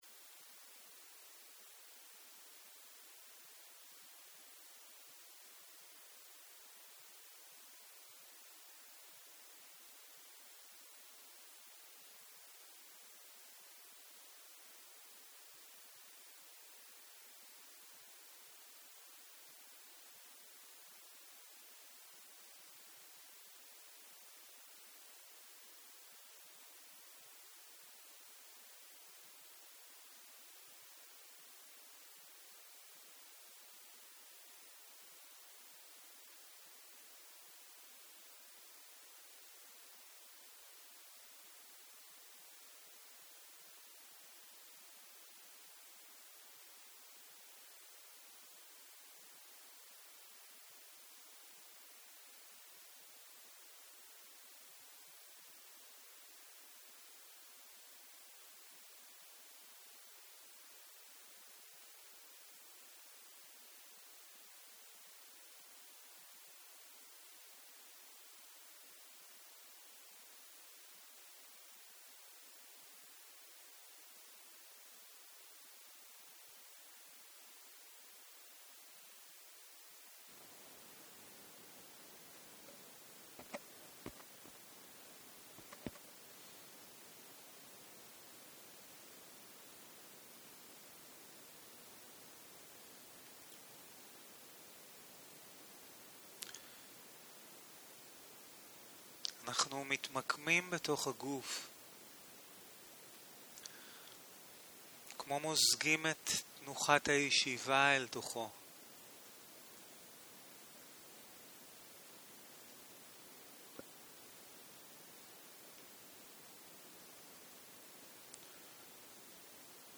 יום 3 - צהרים - מדיטציה מונחית - הקלטה 5